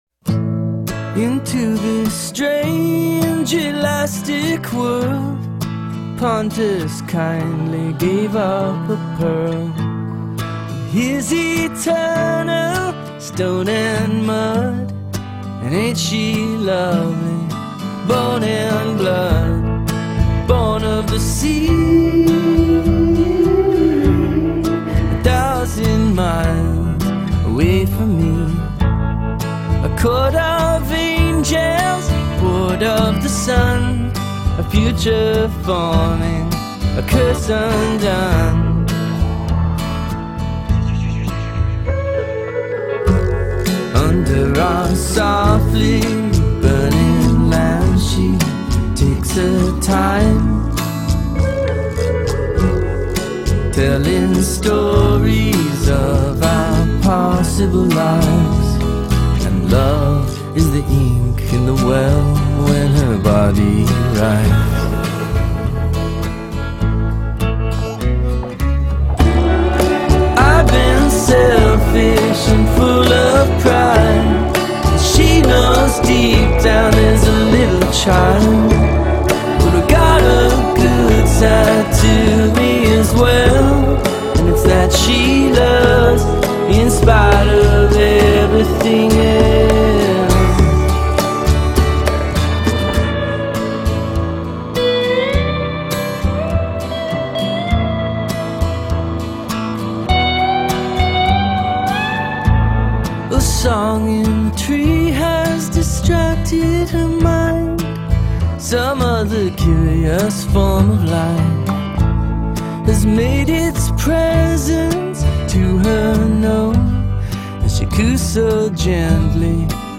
distesa
Il folk pop